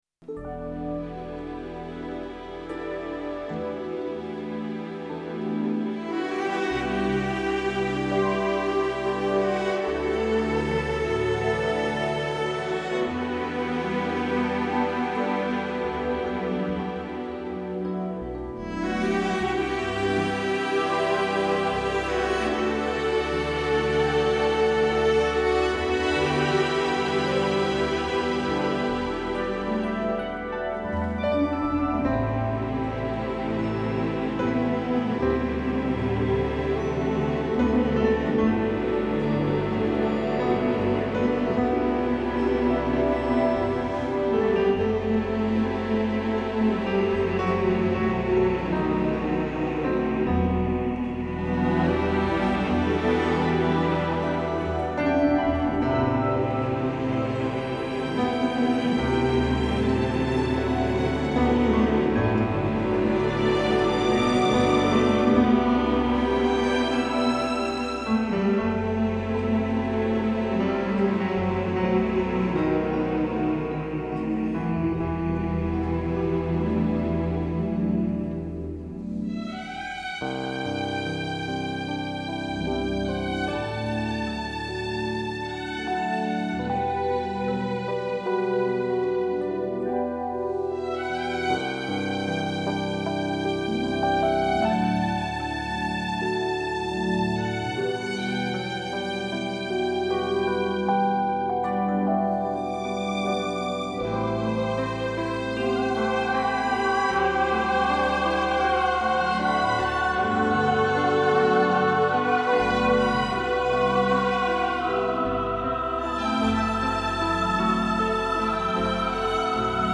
музыка к кино